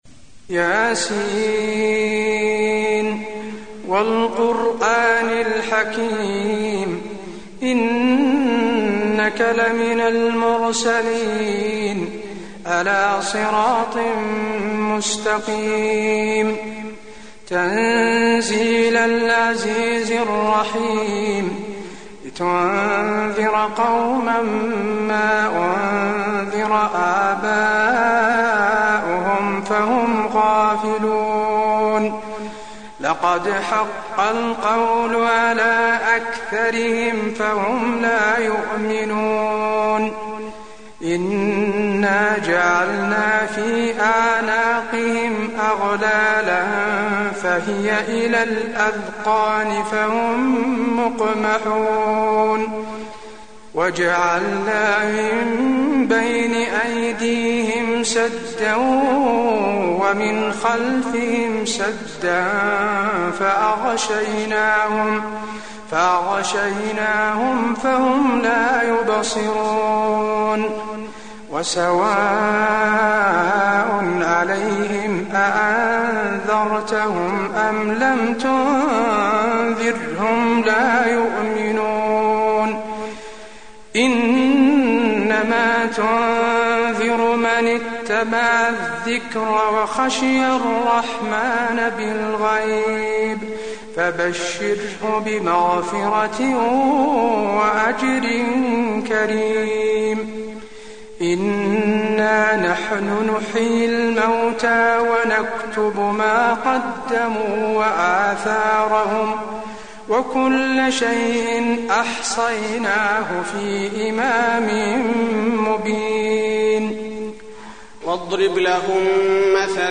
المكان: المسجد النبوي يس